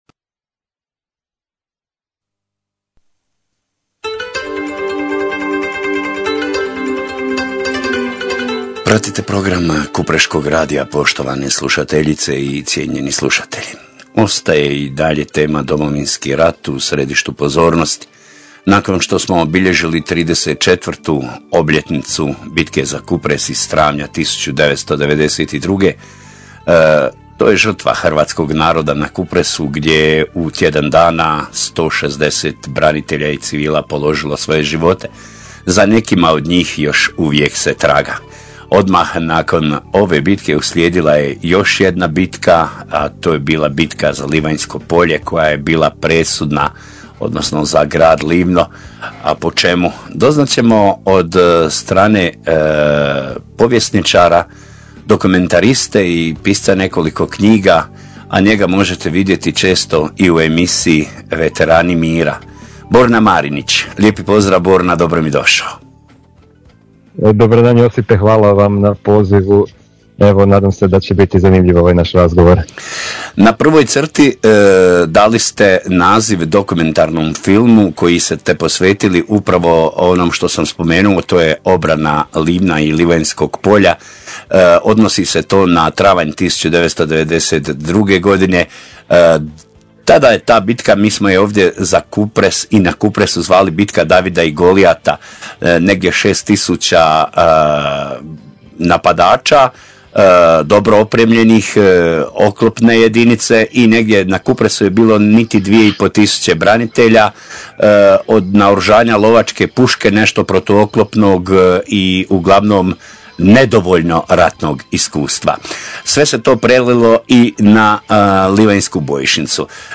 Cijeli razgovor poslušajte ispod: